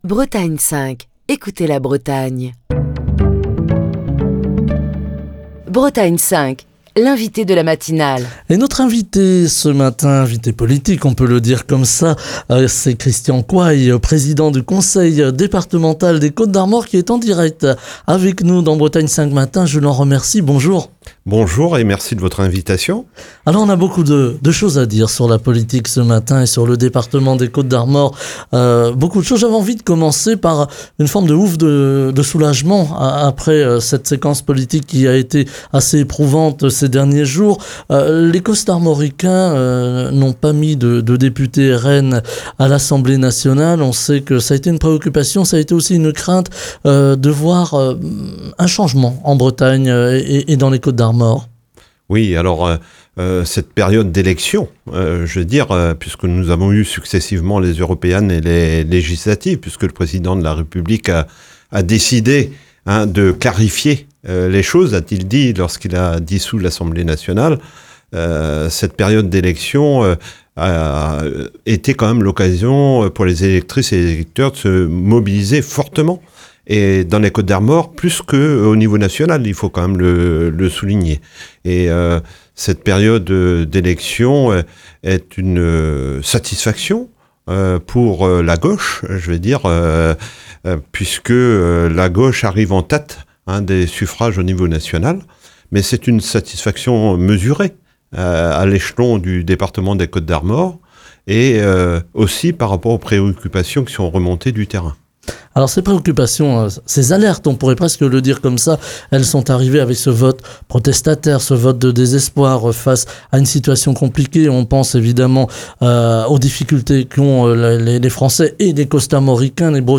Ce mercredi, Christian Coail, président du Conseil départemental des Côtes-d'Armor, est l'invité de la matinale de Bretagne 5. Christian Coail a salué le regain de participation des Costarmoricains lors des deux tours des législatives, se réjouissant qu'aucun député du Rassemblement National n'ait été élu dans la région, et que l'extrême droite ne détienne pas de majorité à l'Assemblée nationale.